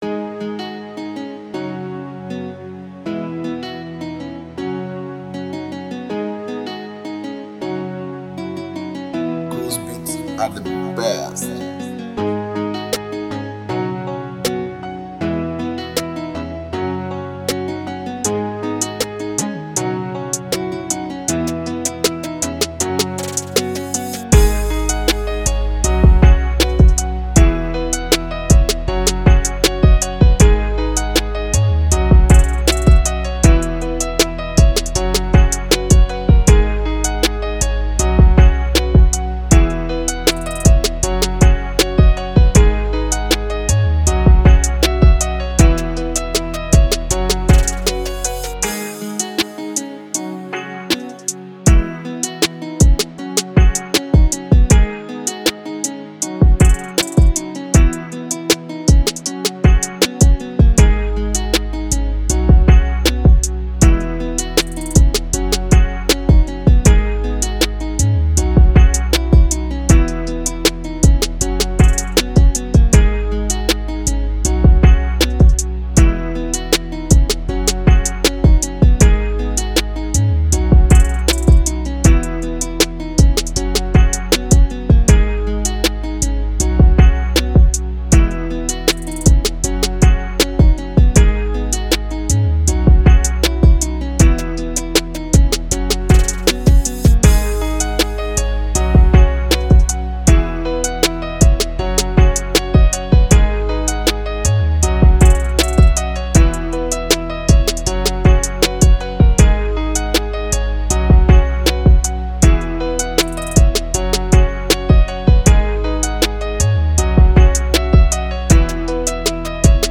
03:46 Genre : Venrap Size